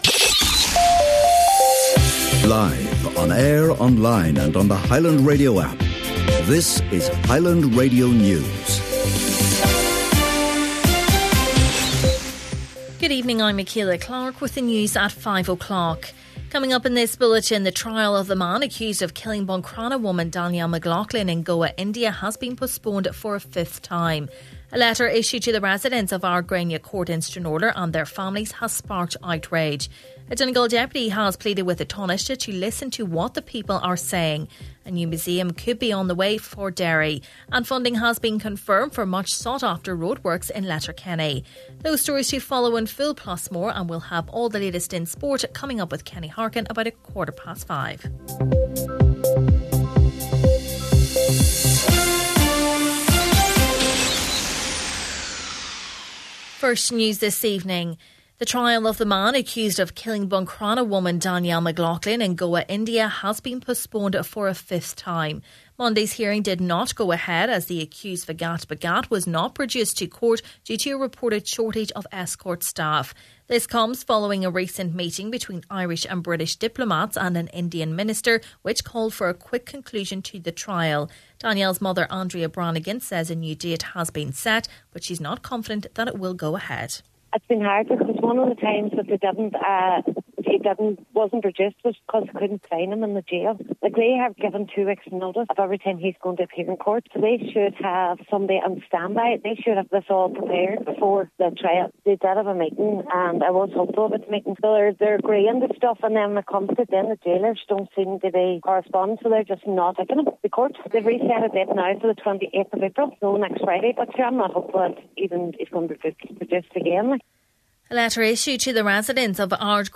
Main Evening News, Sport, Farming News and Obituaries – Thursday April 20th